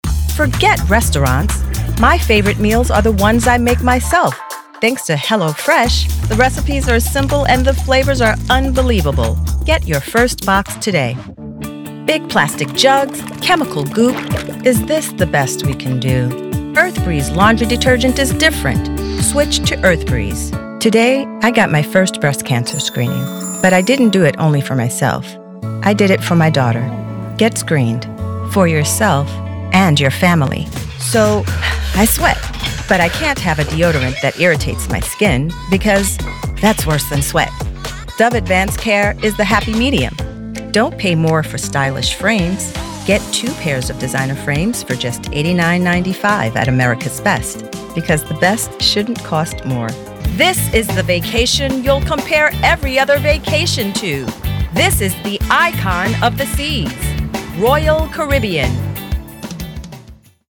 Commercial Demo
Neutral American accent with a warm, grounded delivery.
Middle Aged